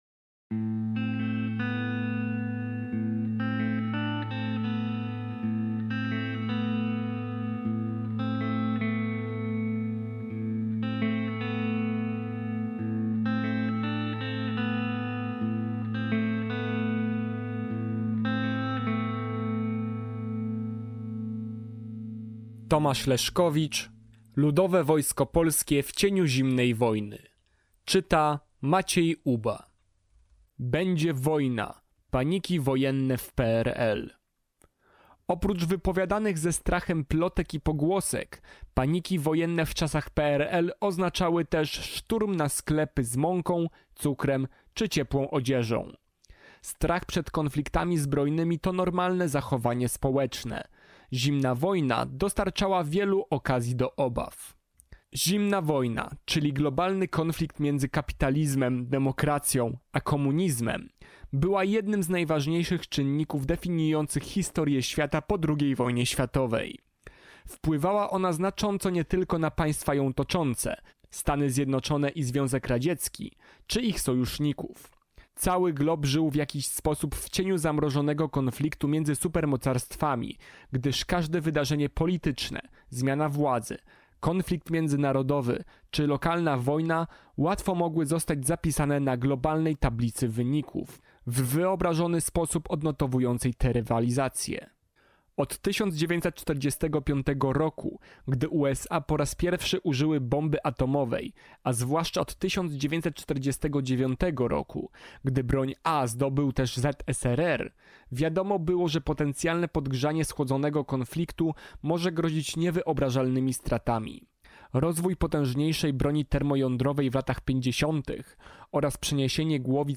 Posłuchaj fragmentu książki: MP3